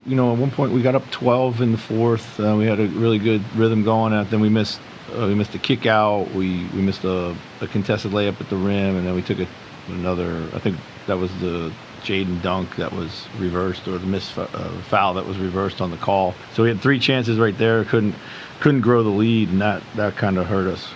Wolves head coach Chris Finch says things unraveled late in the fourth quarter again.